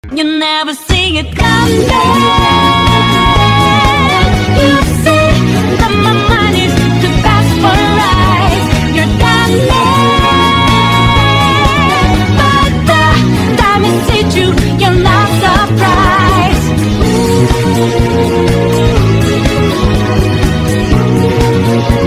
Extract of a soundtrack song
fast-paced jazz-funk track